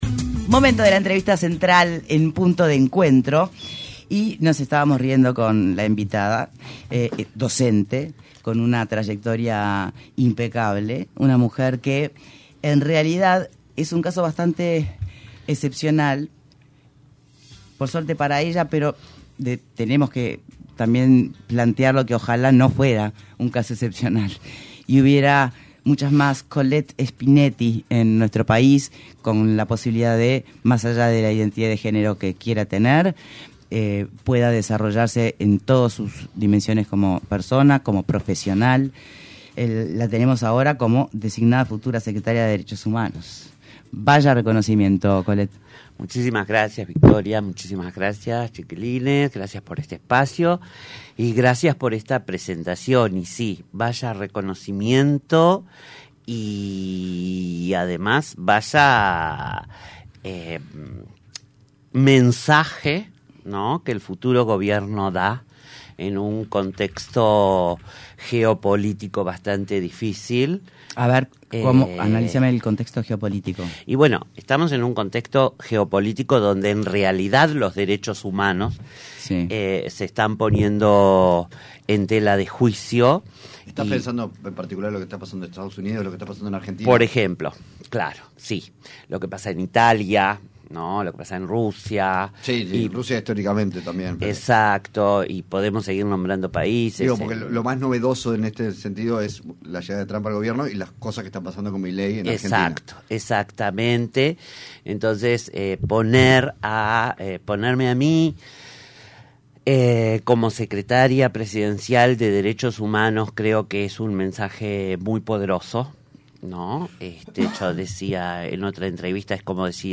Entrevista a Collette Spinelli